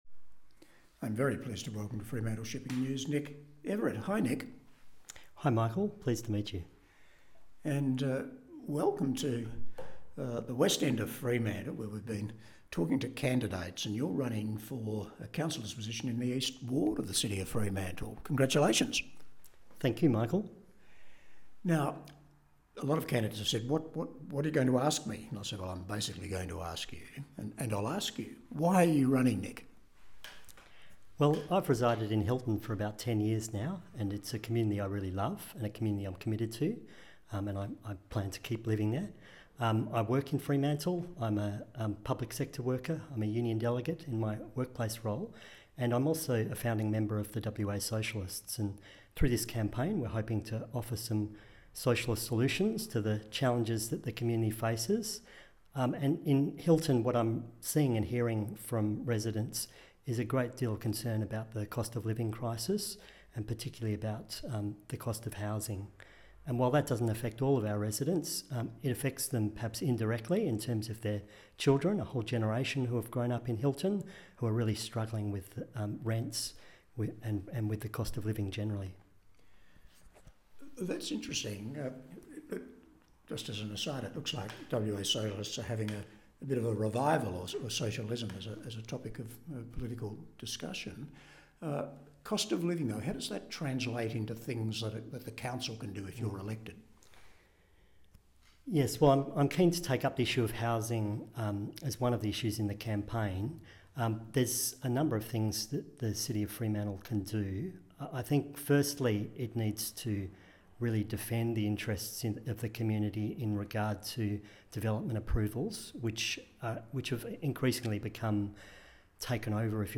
The East Ward Candidates Interviews
We have invited all candidates to be interviewed by our Editor in a getting-to -know-you podcast format explaining why they are running.